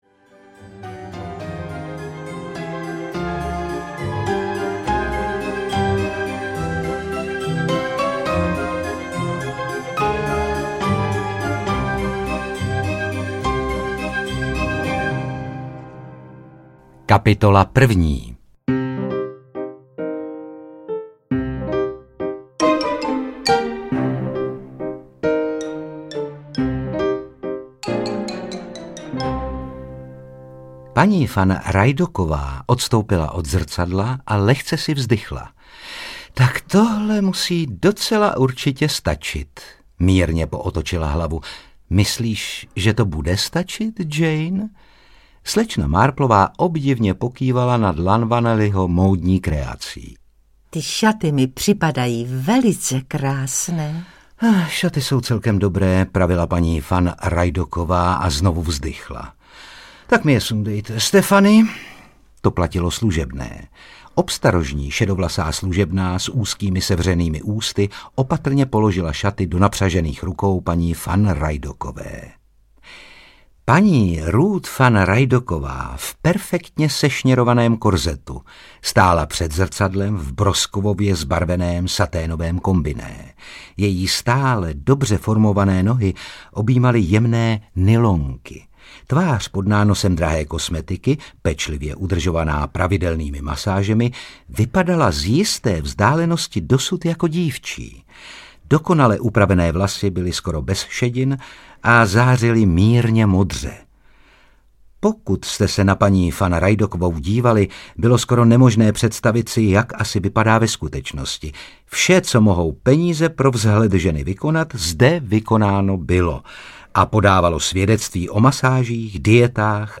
Smysluplná vražda audiokniha
Ukázka z knihy
• InterpretRůžena Merunková, Otakar Brousek ml.